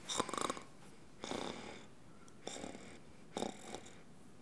baillement_03.wav